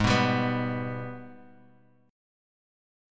G#mM7 chord